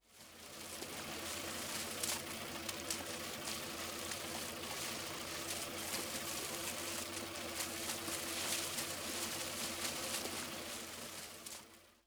Movimiento de unas hélices pequeñas
girar
hélice
Sonidos: Especiales
Sonidos: Industria